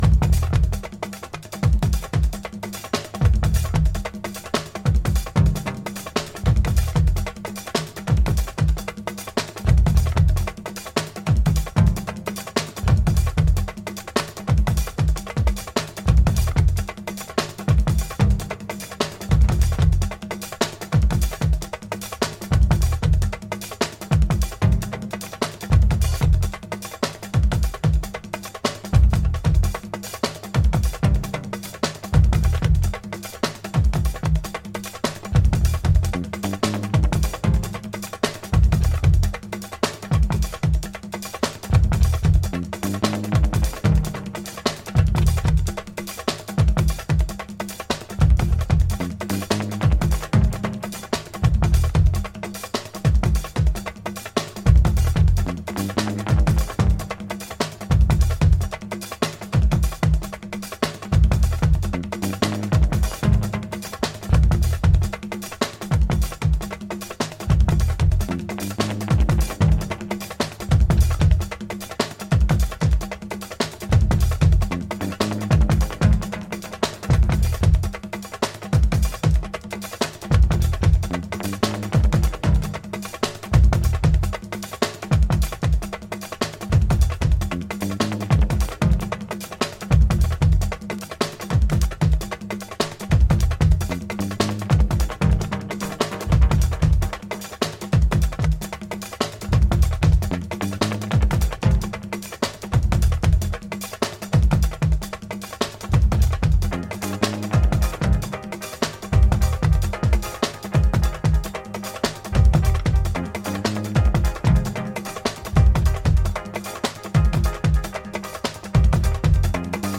JUNGLE/BREAKBEAT